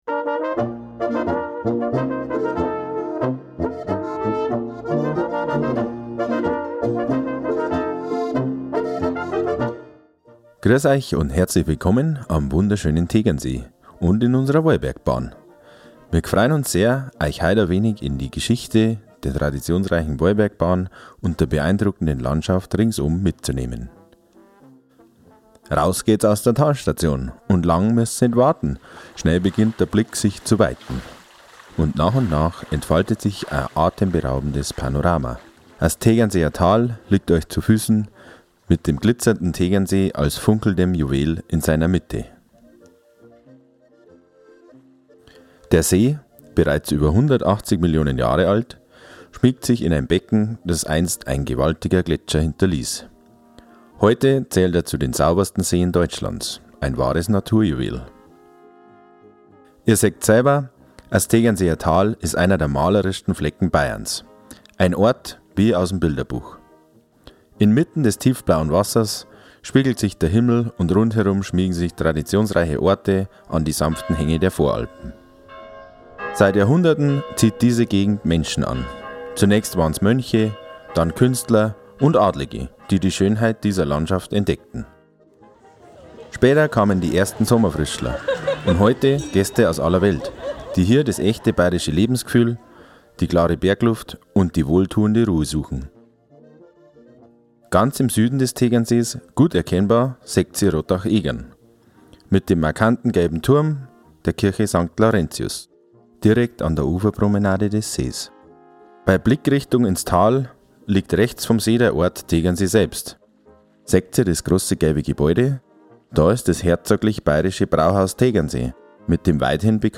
Hörgenuss in der Bahn - Neu: Audioguide zur Auffahrt auf den Wallberg - In den Kabinen unserer Bahn findet Ihr seit der Wintersaison einen QR-Code, der Euch zu einem Audioguide führt.